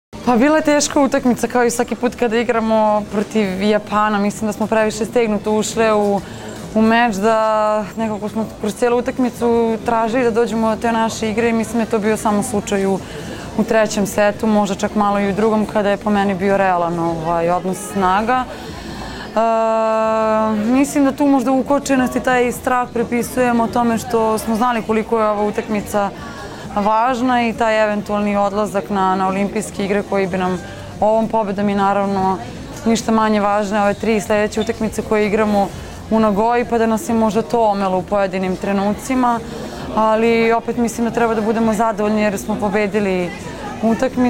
IZJAVA MAJE OGNJENOVIĆ